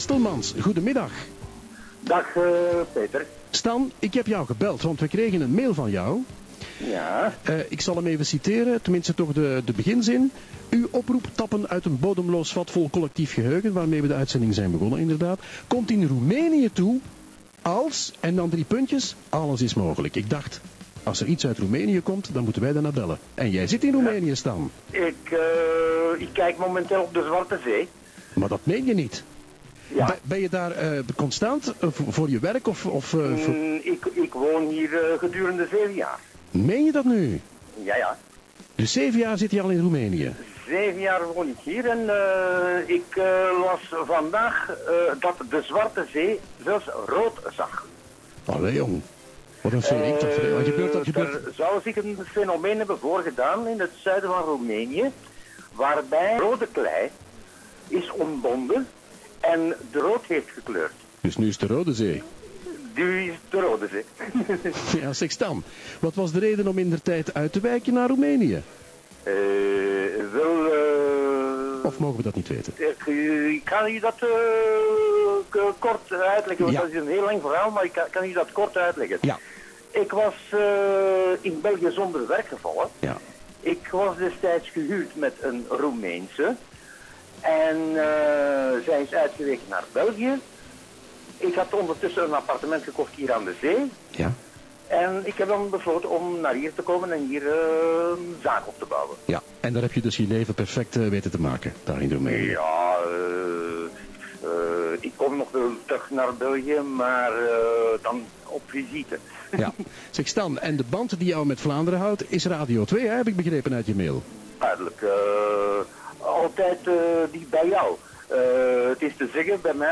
zo kwam ik life op de radio.